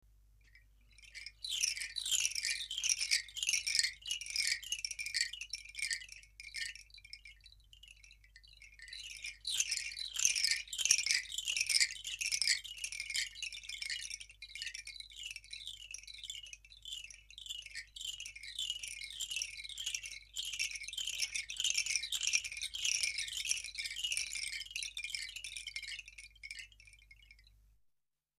竹チャイム